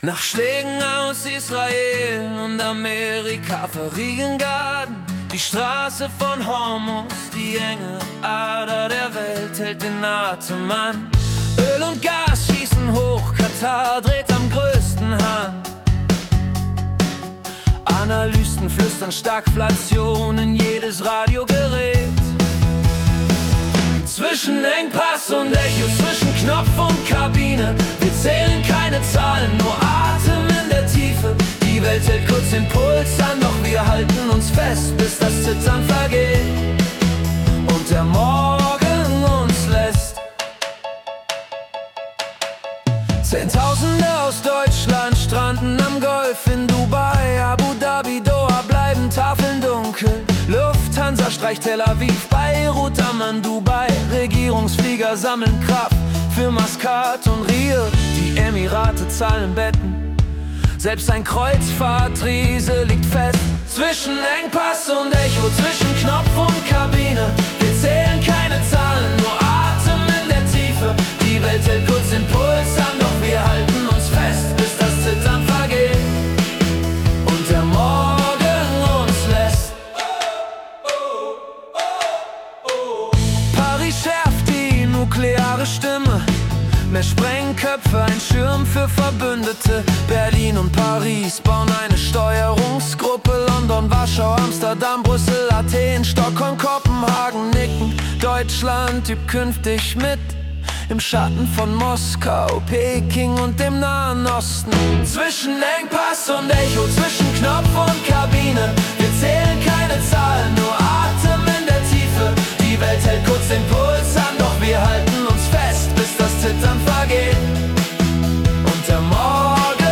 März 2026 als Singer-Songwriter-Song interpretiert.